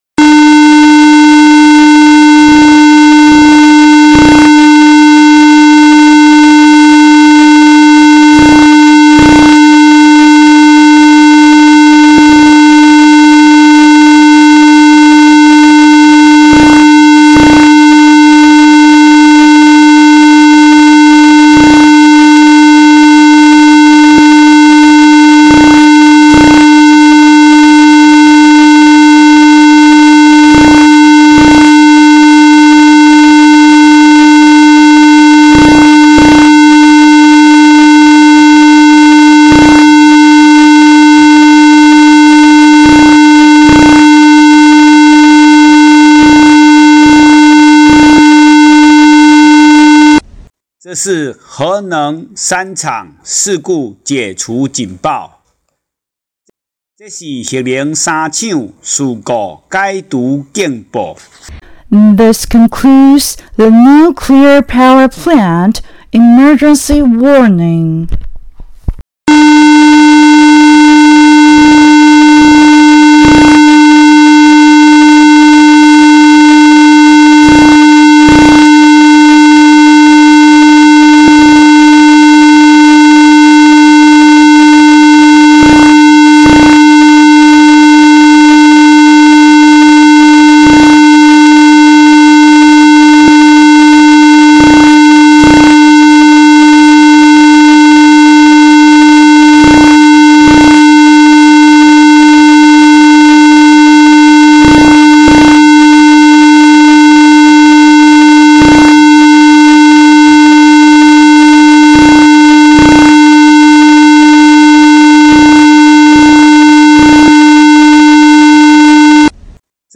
核子事故解除警報聲(♫)